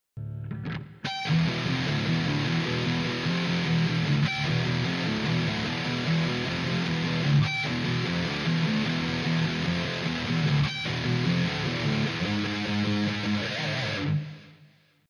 Pour Halo par exemple c' est simplement une harmonique a la 5ème case , jouée a l' unison avec chorus et le gain au taquet
Voila un petit sample enregistré a l' arrache en 2 mins pour te montrer l' effet , je pense que c' est de celui là que tu parles ?
classe le son de l'har artificiel donc chorus et gain au taquet
riff MH harmoniques.mp3